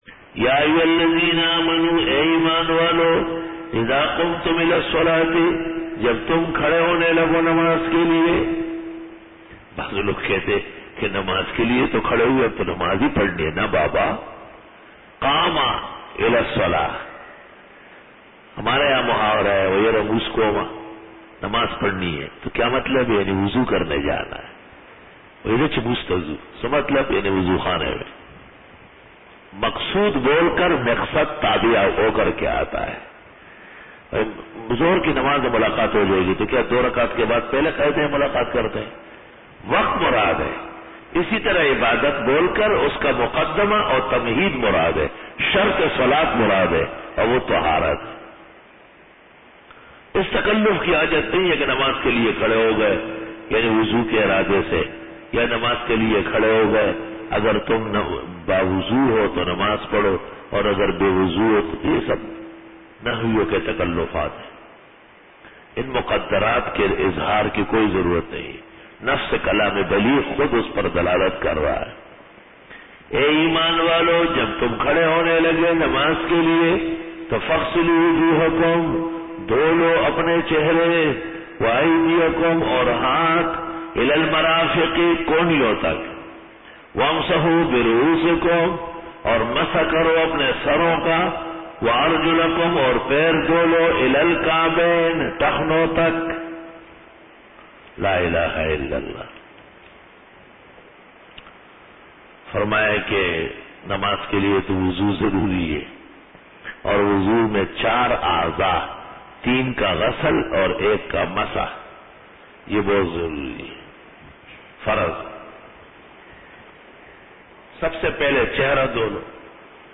Dora-e-Tafseer 2009